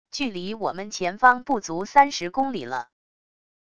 距离我们前方不足三十公里了wav音频生成系统WAV Audio Player